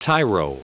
Prononciation du mot tyro en anglais (fichier audio)
Prononciation du mot : tyro